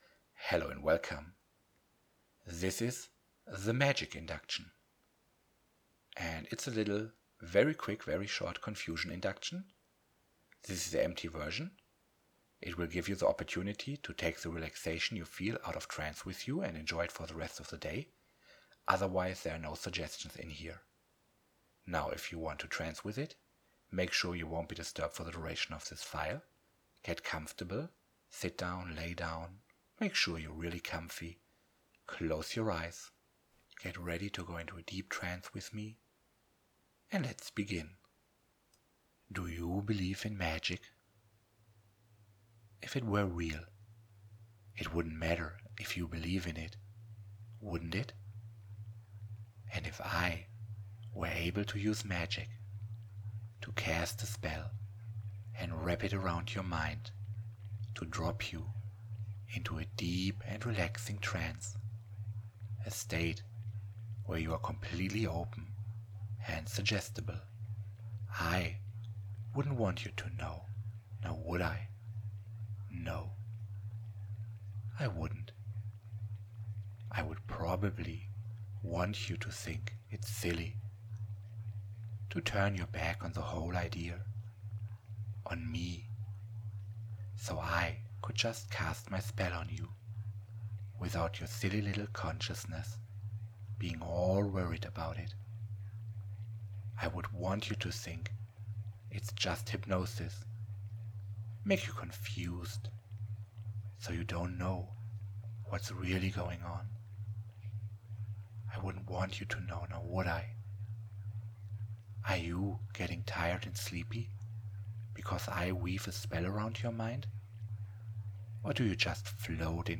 Magic Induction is another very short induction, using the idea of dropping you down magically to get you into trance.
Magic-Induction.mp3